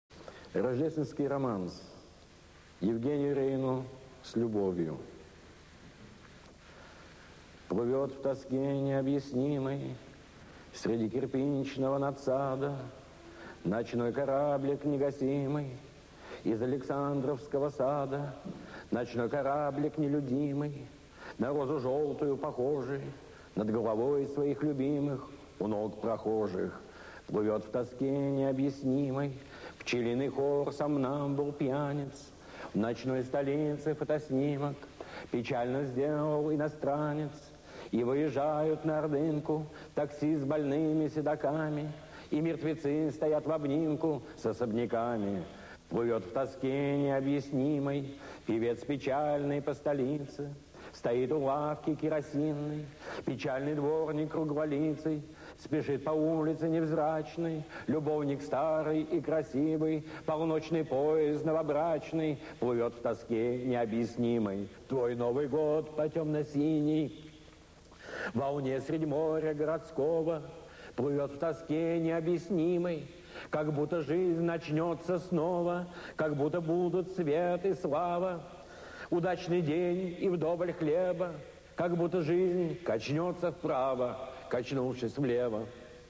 Название: «Рождественский романс», автор: Бродский Иосиф Александрович , жанр: Поэзия
Рождественский романс. читает Иосиф Бродский..mp3